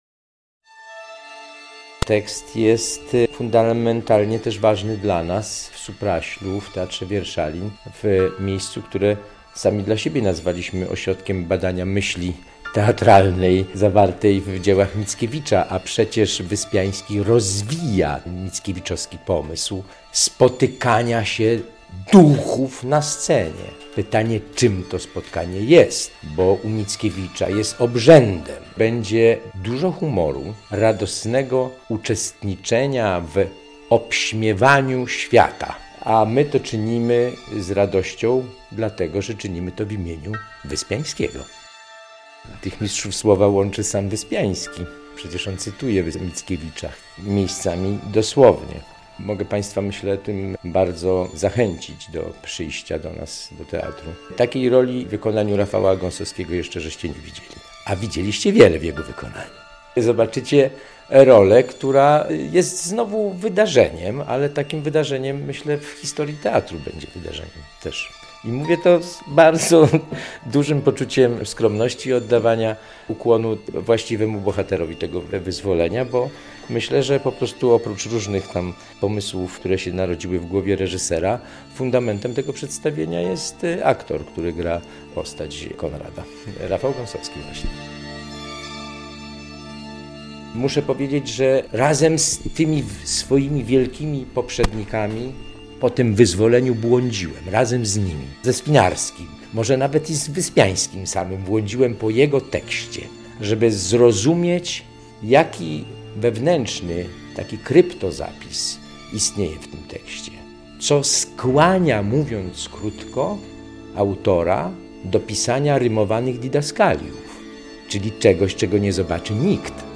"Między wierszami" w Supraślu. Wydarzeniem będzie premiera spektaklu "Wyzwolenie" wg Wyspiańskiego - relacja